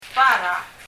« Abogado アボガド brush ブラシ » rose バラ bara [bara] 日本語のまま！